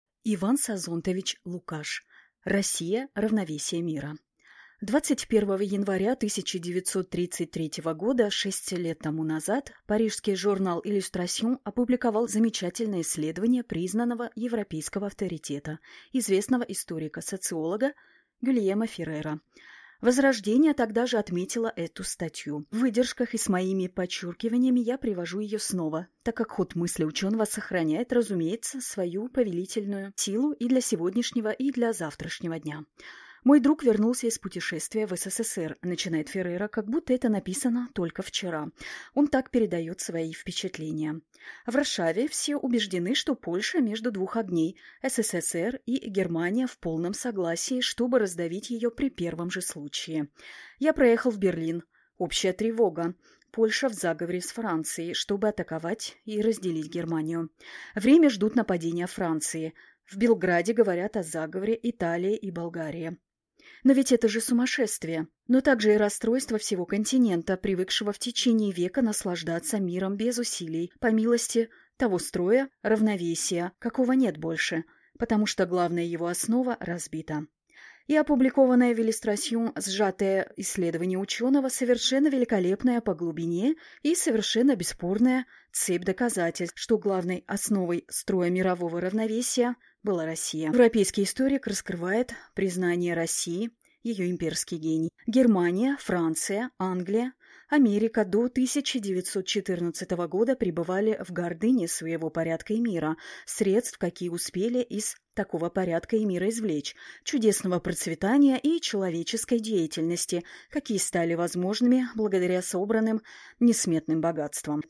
Аудиокнига «Ведьма в академии магии. Чужая невеста».
Звуки и музыка создают атмосферу загадочности и волшебства.